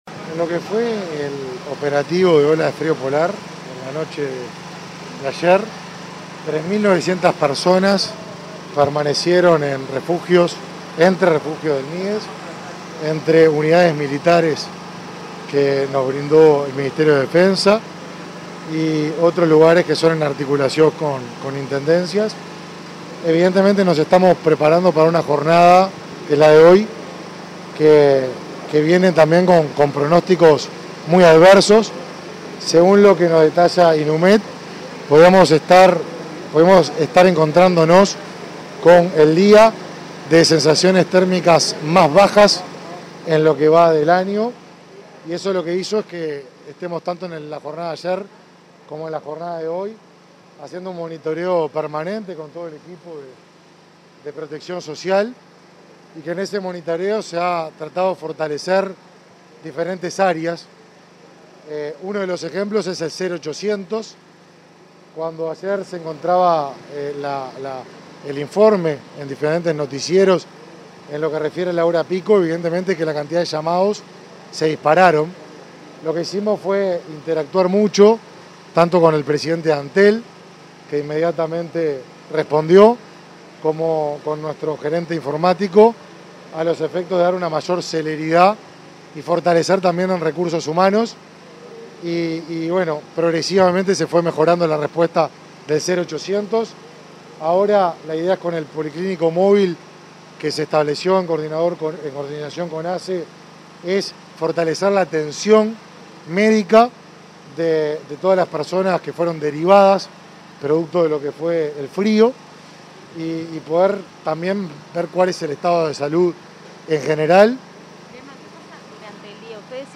Declaraciones a la prensa del ministro Martín Lema